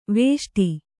♪ vēṣṭi